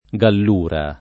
[ g all 2 ra ]